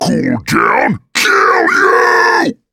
Kr_voice_kratoa_taunt02.mp3